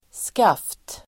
Uttal: [skaf:t]